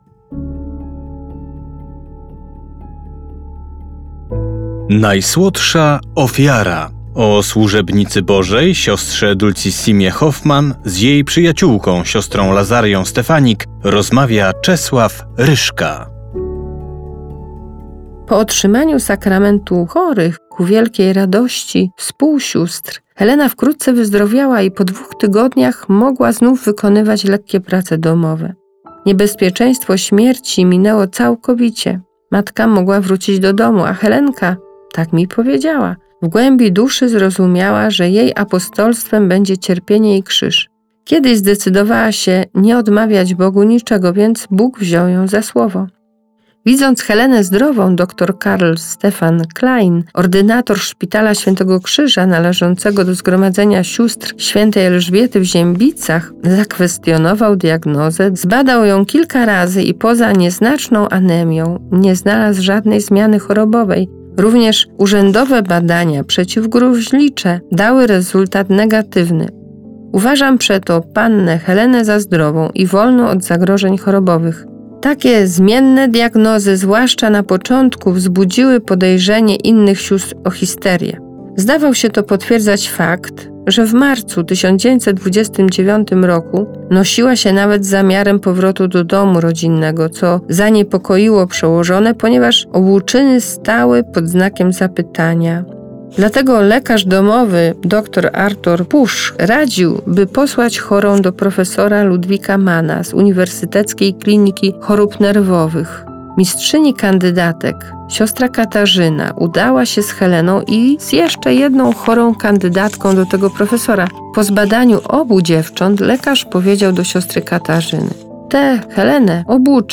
Propozycją duszpasterską Radia Rodzina na Wielki Post jest specjalny audiobook pt. „Dulcissima -Najsłodsza Ofiara”.